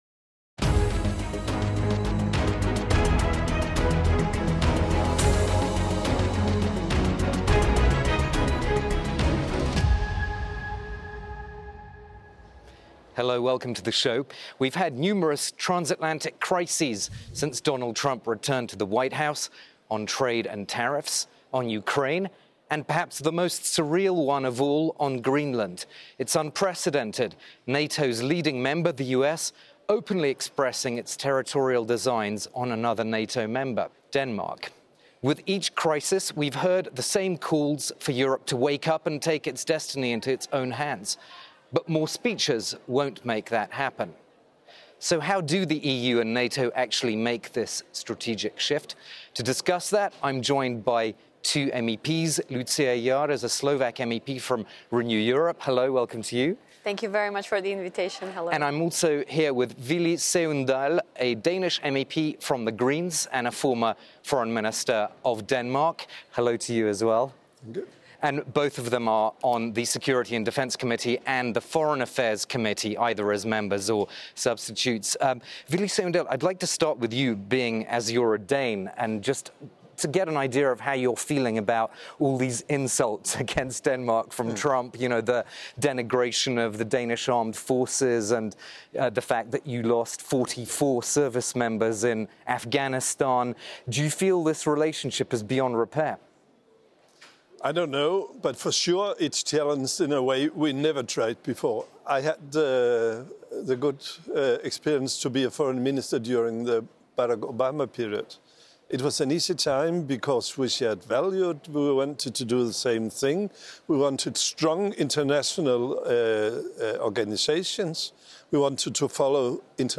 But how should the EU and the European members of NATO actually make a strategic shift? We put the question to two MEPs.